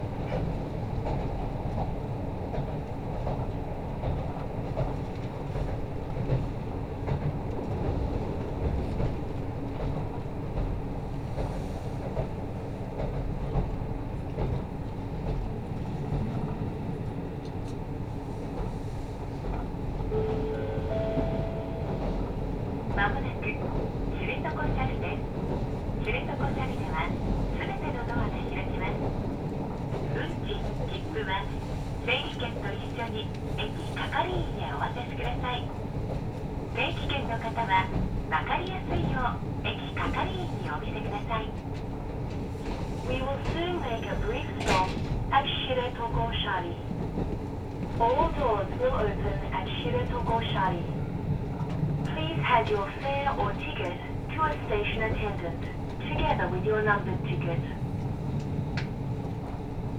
釧網本線の音の旅｜網走発キハ54系始発列車 知床斜里到着アナウンスと走行音
人の気配のない白い海岸を、キハ54系は走り続けます。
波の音も、風の音も、車内には届かない。
聞こえるのは、ただ走行音だけです。
知床斜里駅 到着アナウンス
穏やかな車内アナウンスが静寂を破ります。
0005yambetsu-shiretokoshari.mp3